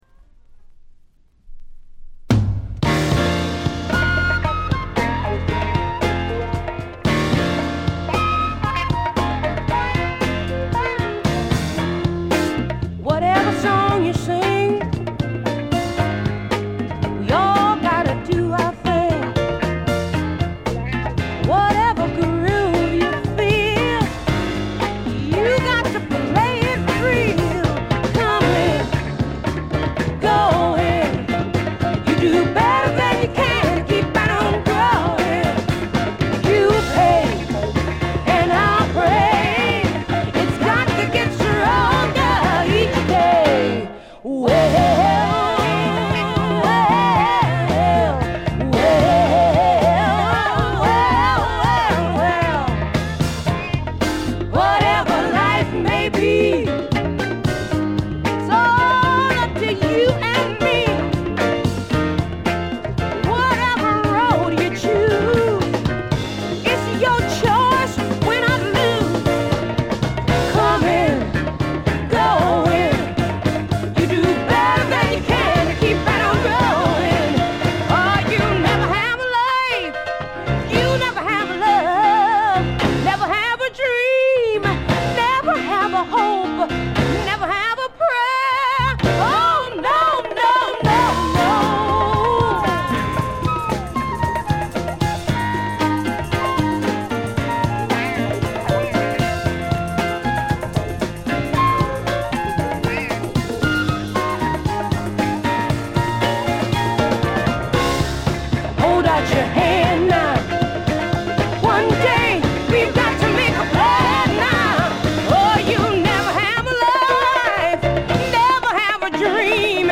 試聴曲は現品からの取り込み音源です。
Recorded At - A&M Studios